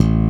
Index of /90_sSampleCDs/Roland - Rhythm Section/BS _E.Bass 4/BS _Stretch Bass